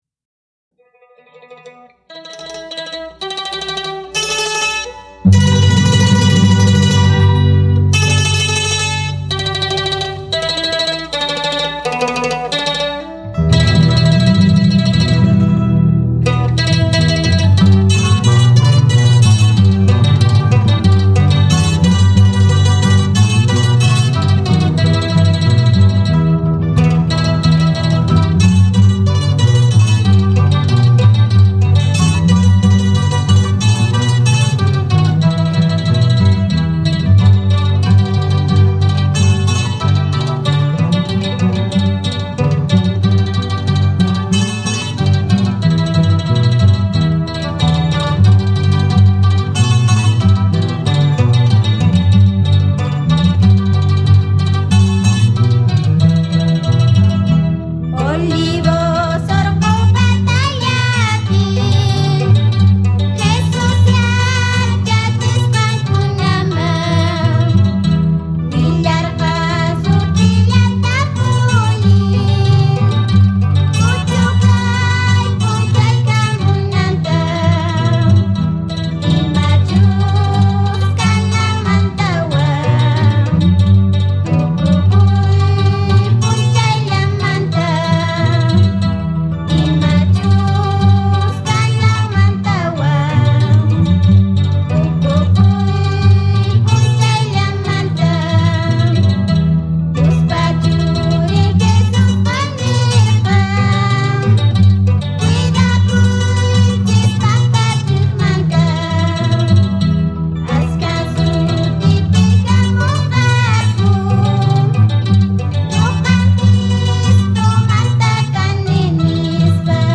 Mandolina
Guitarra
Vocalista